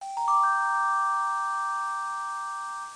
chime2.mp3